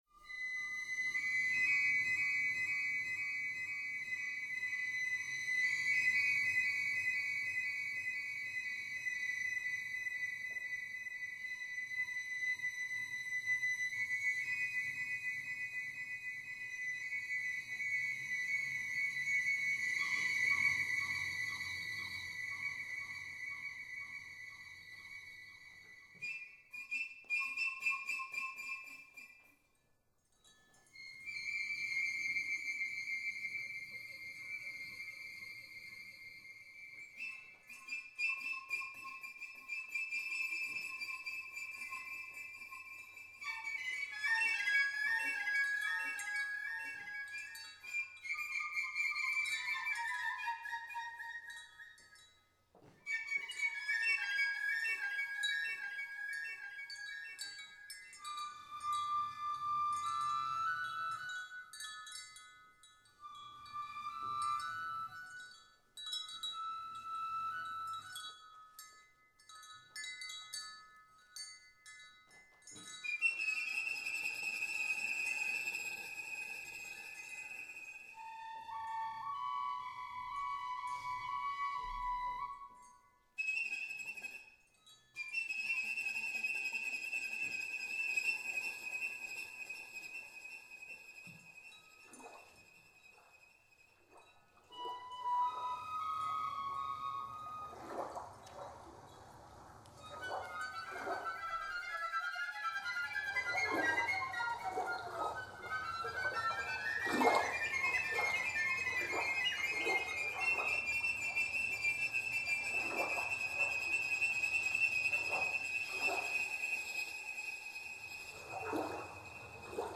Lost Warblers in the Wood (piccolo and electronics)
Student Composers Recital.